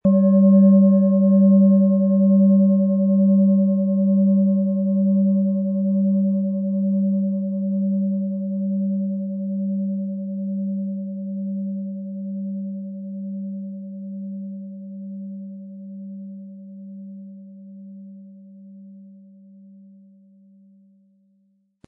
Von Hand getriebene Klangschale mit dem Planetenklang Tageston aus einer kleinen traditionellen Manufaktur.
• Mittlerer Ton: Mars
Sie möchten den Original-Ton der Schale hören? Klicken Sie bitte auf den Sound-Player - Jetzt reinhören unter dem Artikelbild.
MaterialBronze